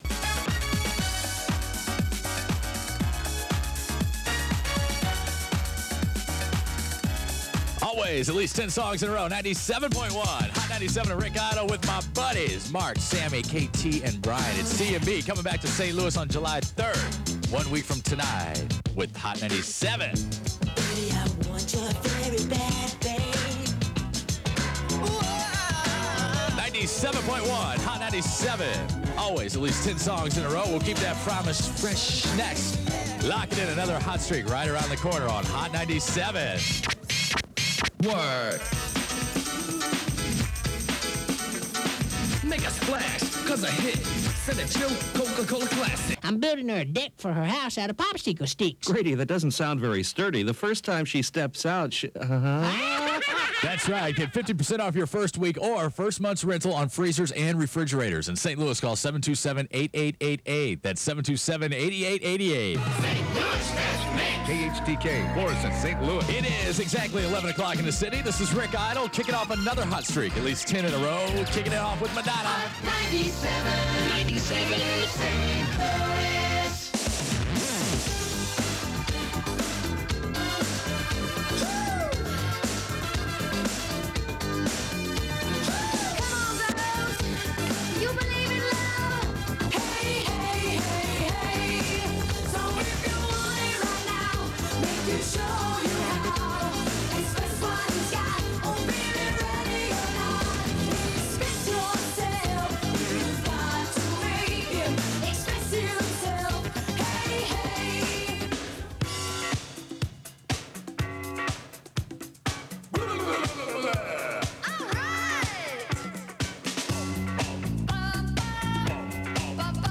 Aircheck